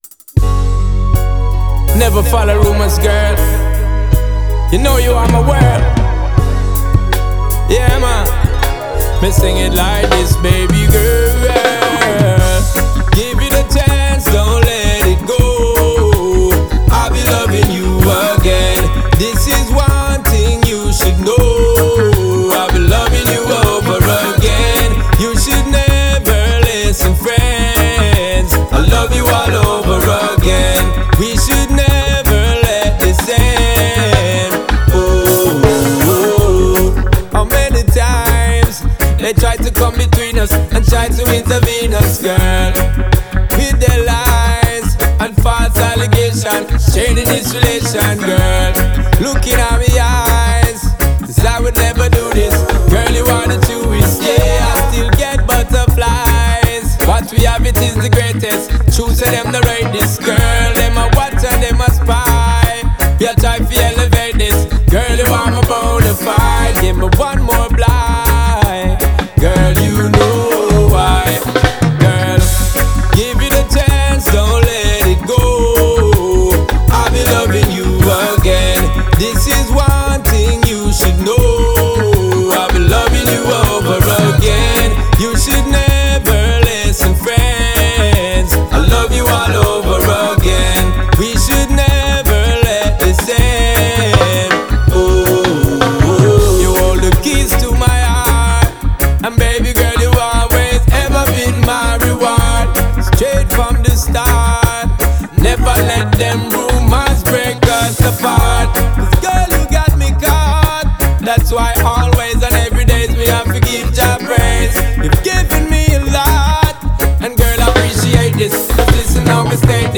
энергичная регги-танцевальная композиция